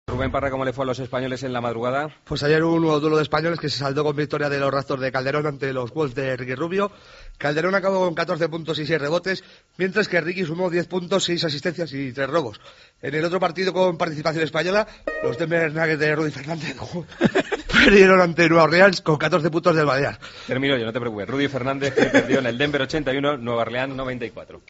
Los gazapos